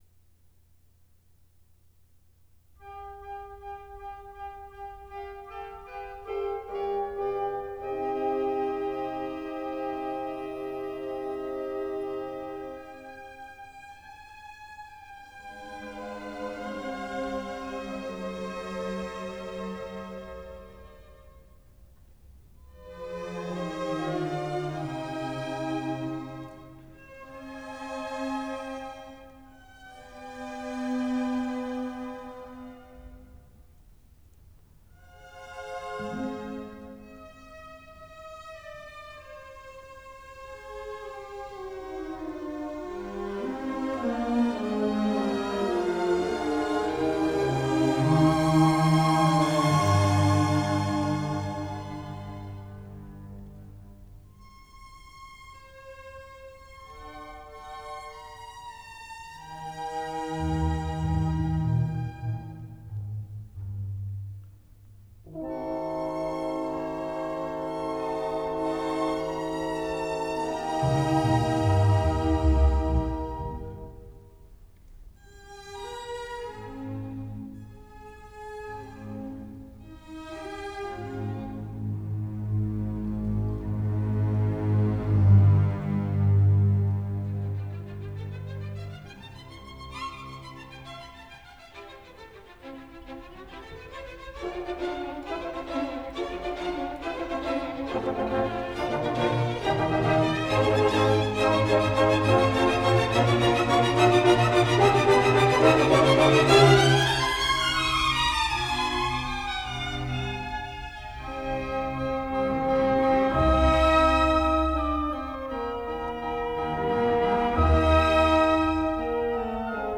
Artist(s): Paul Paray conducts the Detroit Symphony Orchestra
Transferred from a 15ips 2-track tape
in the Cass Technical High School Auditorium, Detroit